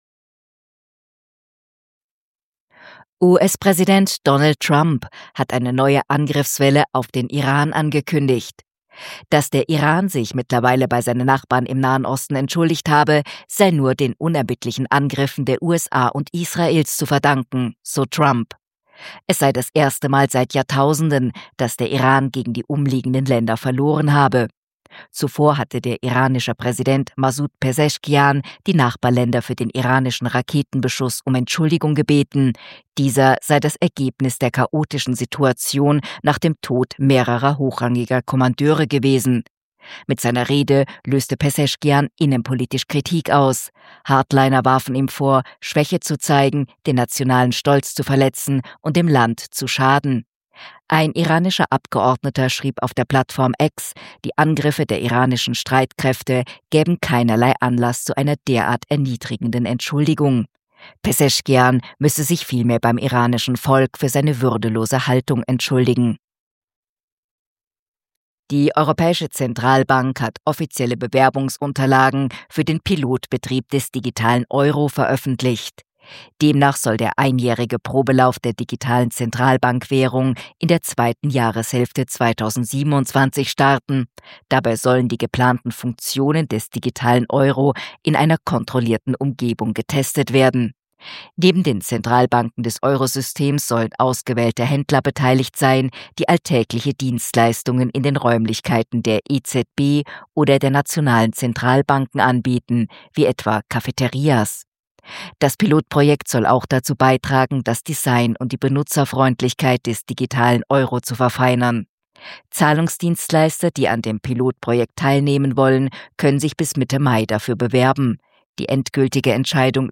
Kontrafunk Wochenrückblick 7.3.2026 – Nachrichten vom  8.3.2026